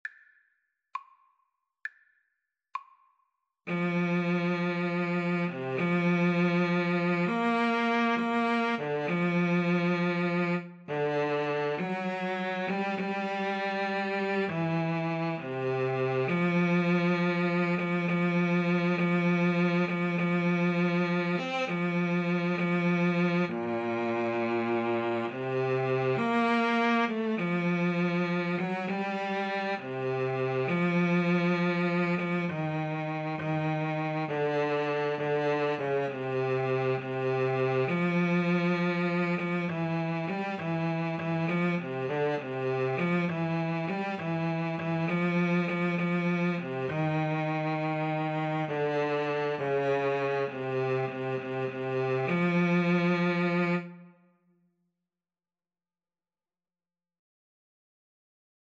6/8 (View more 6/8 Music)
Classical (View more Classical Saxophone-Cello Duet Music)